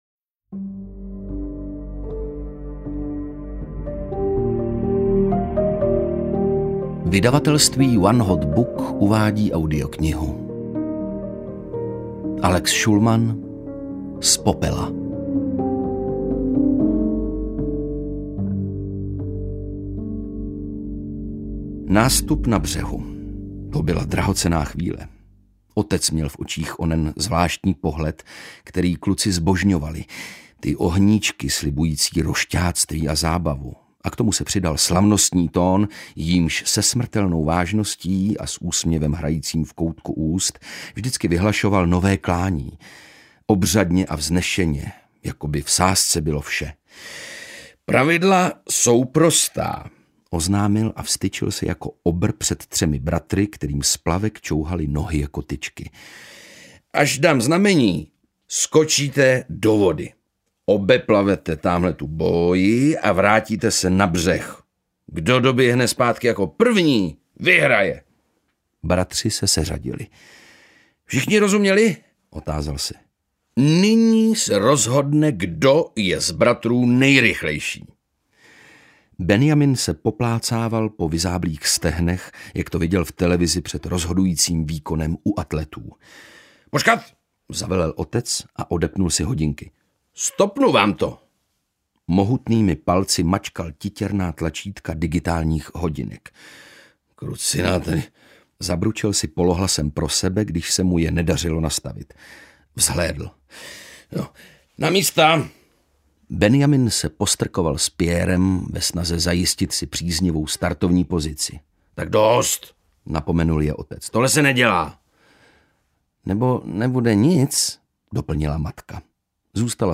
Z popela audiokniha
Ukázka z knihy
• InterpretOndřej Brousek